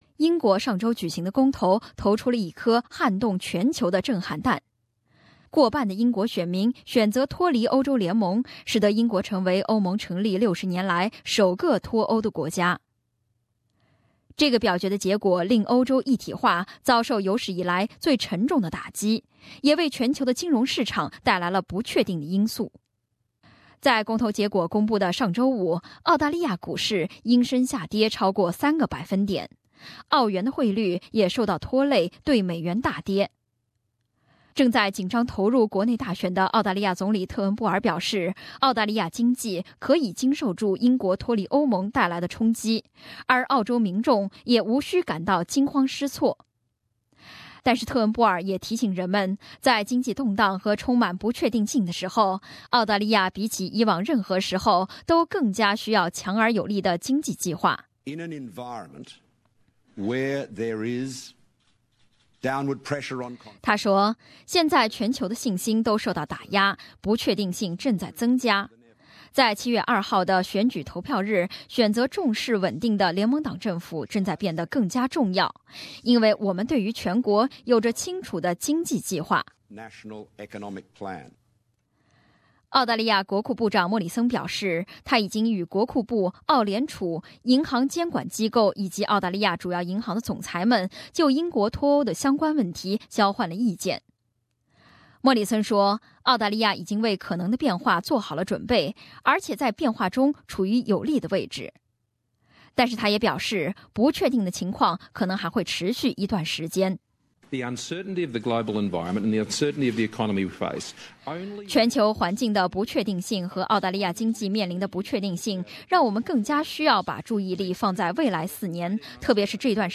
Australian Prime Minister Malcolm Turnbull speaks to the media on the referendum in the UK to leave the European Union.